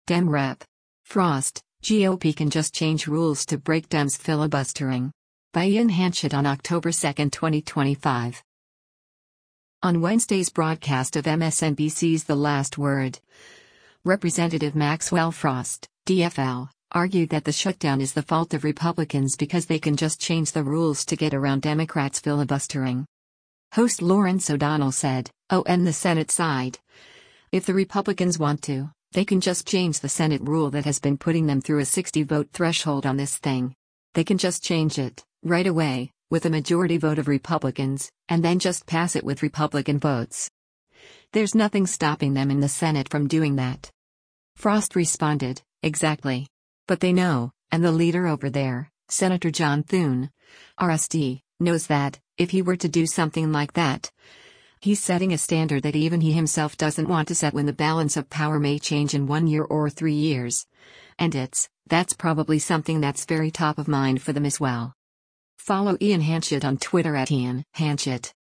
On Wednesday’s broadcast of MSNBC’s “The Last Word,” Rep. Maxwell Frost (D-FL) argued that the shutdown is the fault of Republicans because they can just change the rules to get around Democrats filibustering.